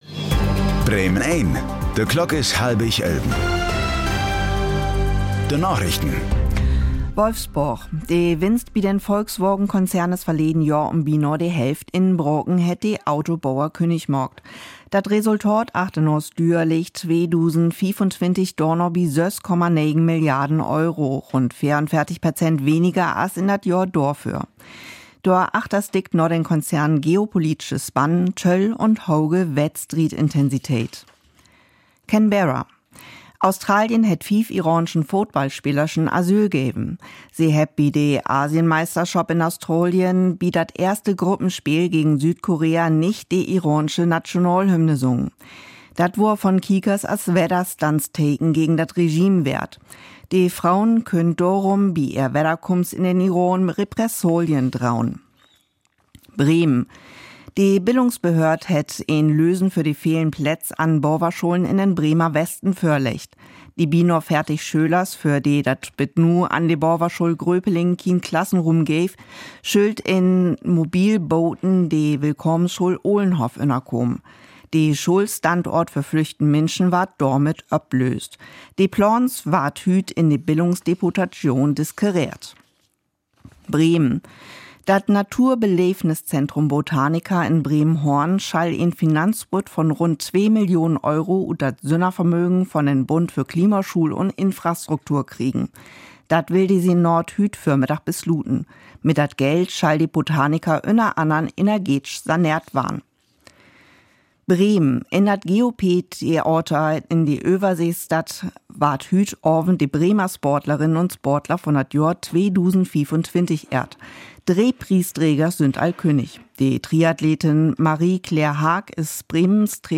Plattdüütsche Narichten vun'n 10. März 2026